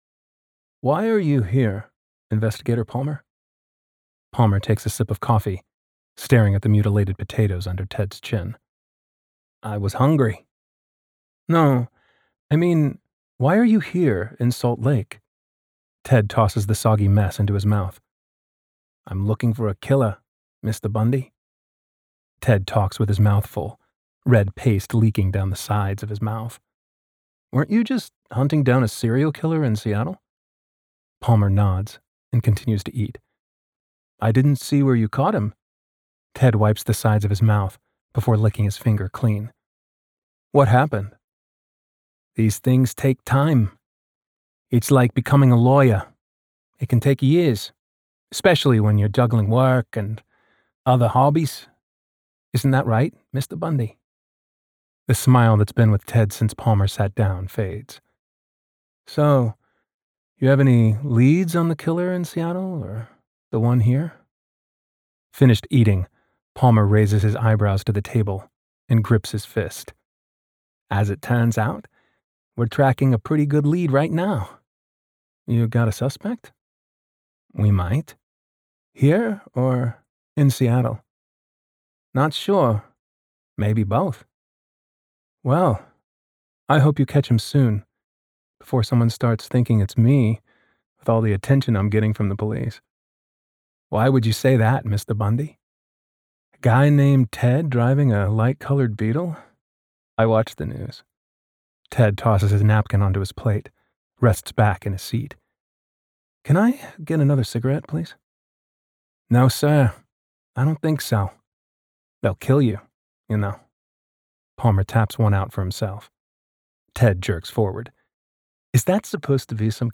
AUDIOBOOK NARRATION
Serial-Killer-Mystery-Sample.wav